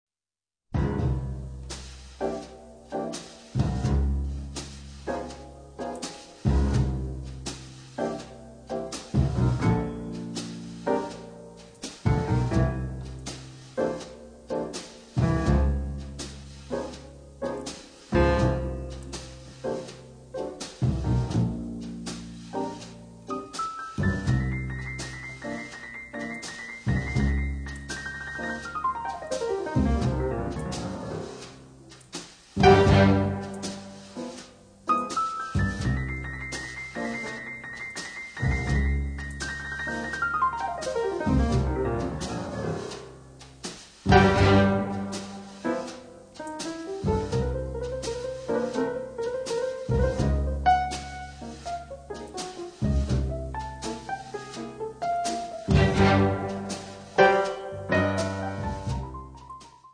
pianoforte
contrabbasso
batteria
Strings Orchestra